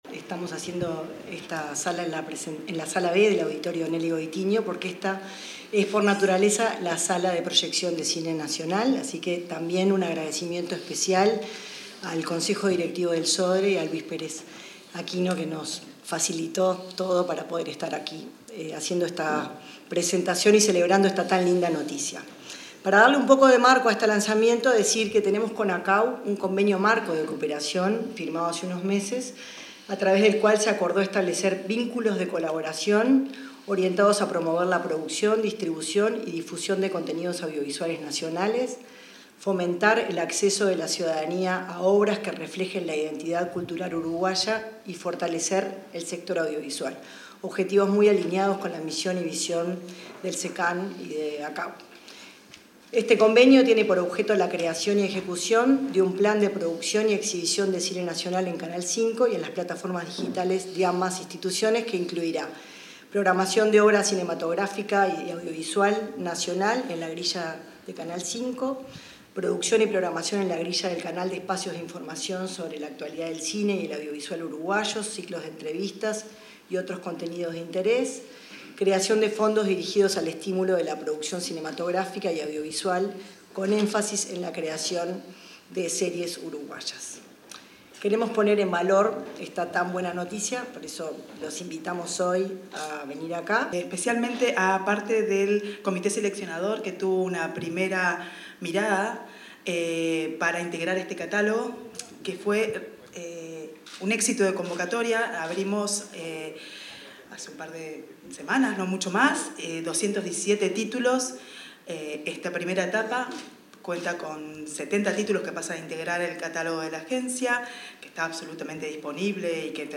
Palabras de autoridades en presentación del ciclo "El cine de los uruguayos"
En ocasión del lanzamiento se expresaron el ministro de Educación y Cultura, José Carlos Mahía; la directora del Servicio de Comunicación Audiovisual